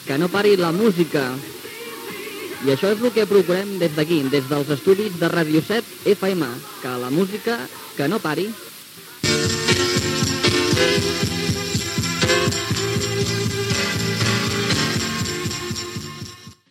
Identificació de l'emissora i tema musical